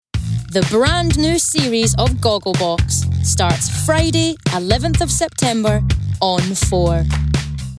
Scottish
Female
Friendly
Warm